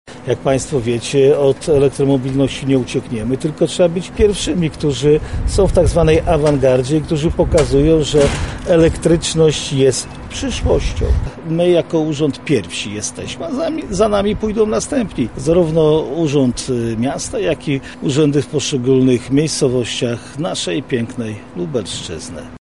• mówi marszałek województwa lubelskiego Jarosław Stawiarski.